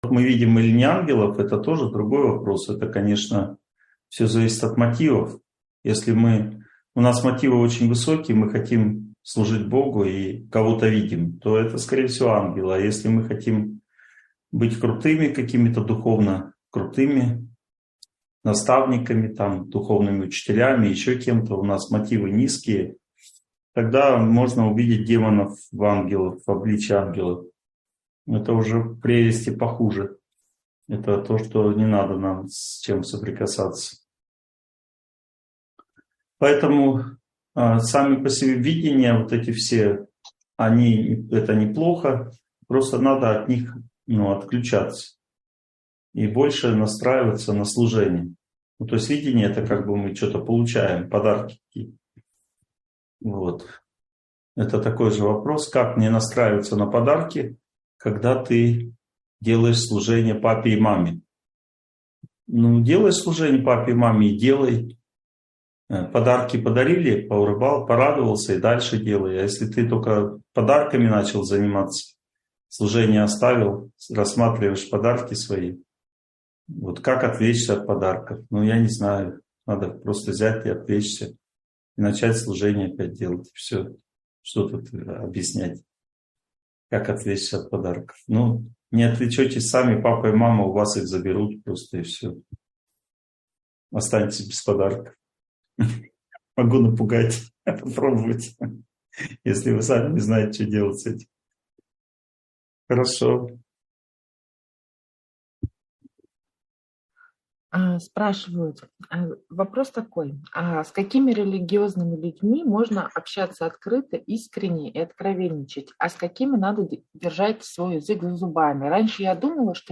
Духовные отношения. Как их строить правильно (онлайн-семинар, 2023)